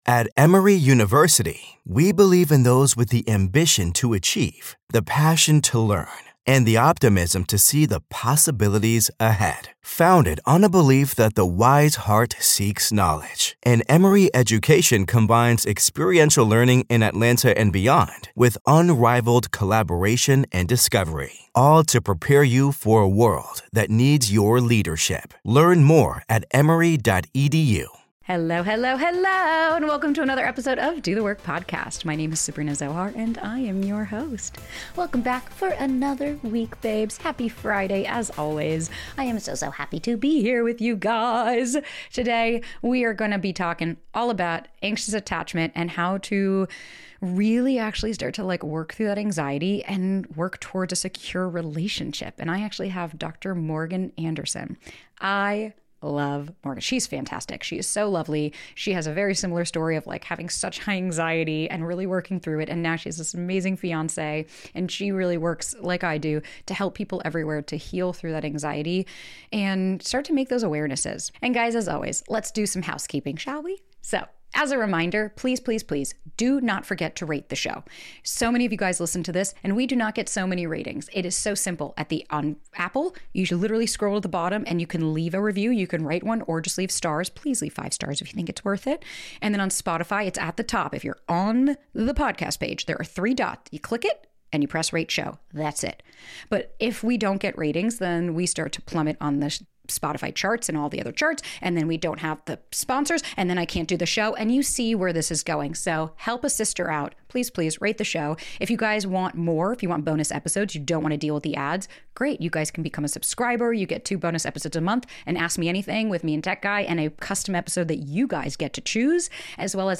Sitting down in the studio today